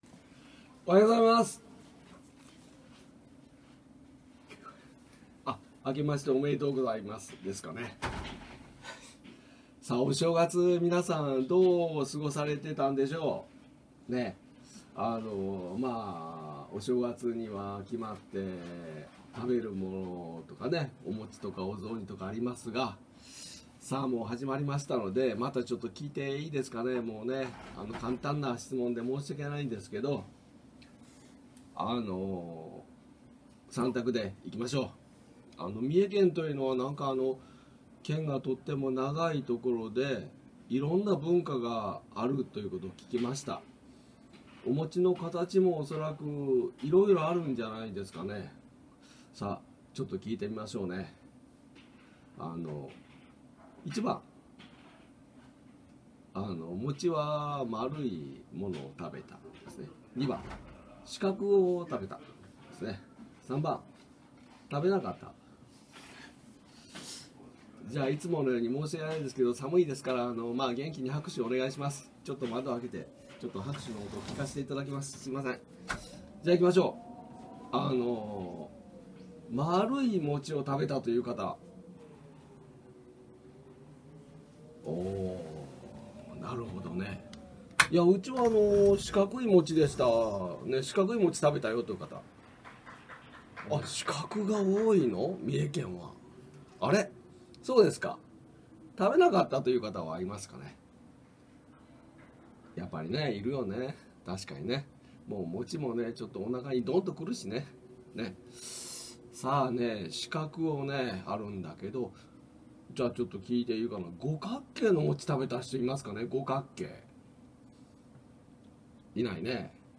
３学期始業式
１月８日(金)...始業式の話【校長先生】（音声のみ1.48MB程度）